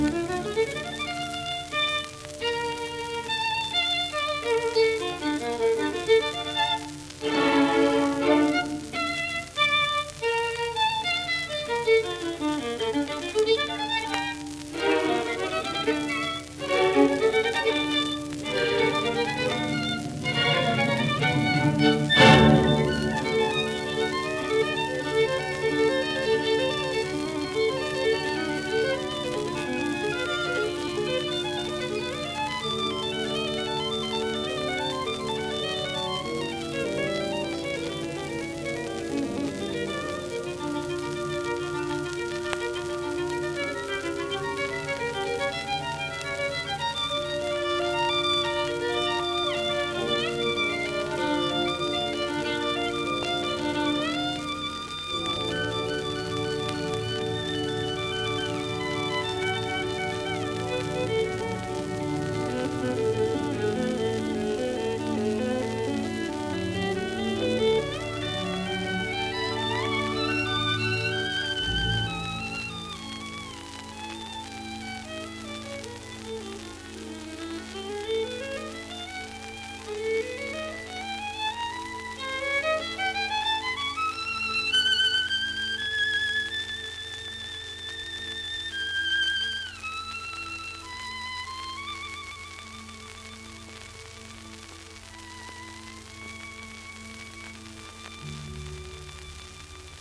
盤質A- *軽微な小キズとソリ
1936年録音